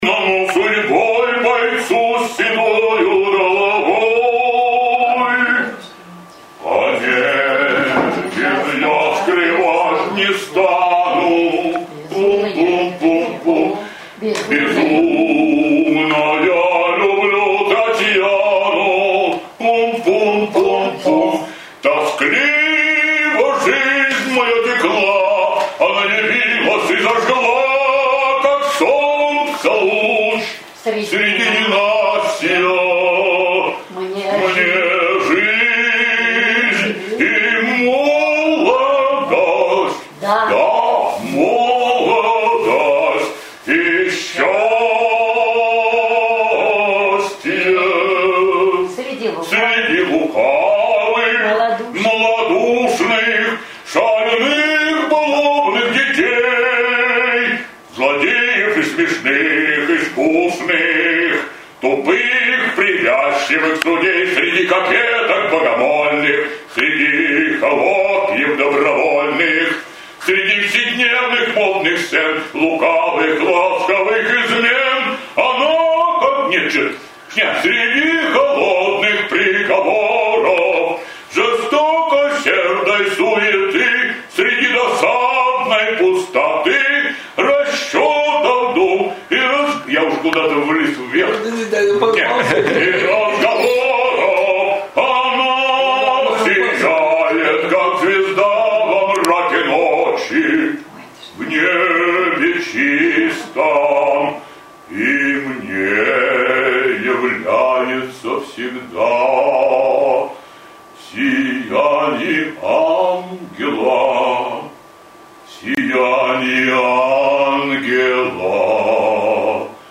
арии князя из оперы "Евгений Онегин", записанное в стенах Института в 2009 г.